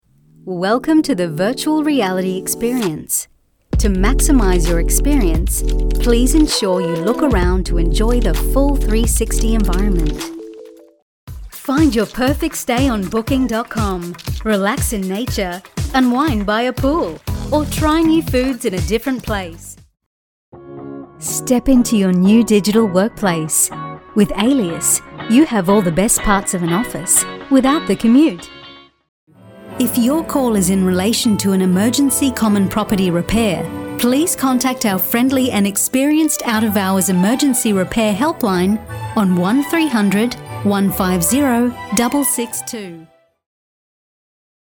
My voice is authentic, smooth and can be raspy at times. It is professional, articulate, trustworthy, assertive, warm, inviting and believable.
English (North American) Adult (30-50) | Older Sound (50+)